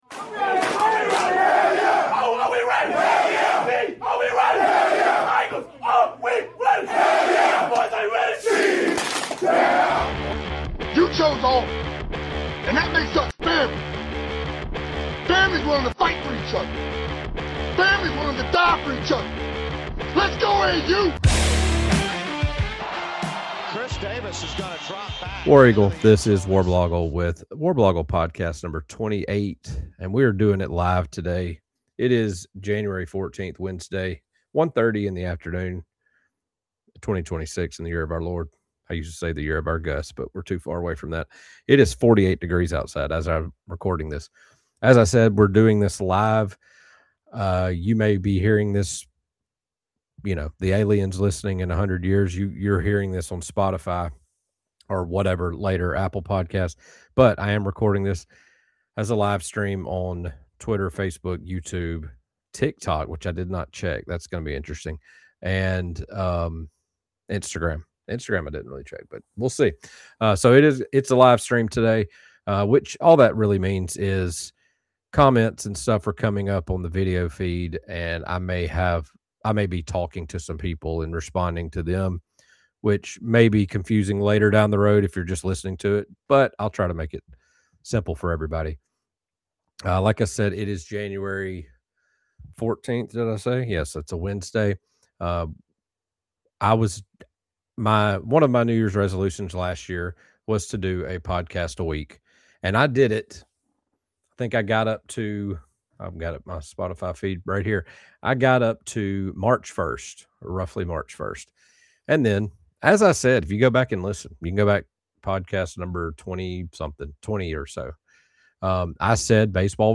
In this live-streamed episode, I discuss everything wrong with the transfer portal, and eventually have a small Festivus moment to get some things about 2025 off my chest.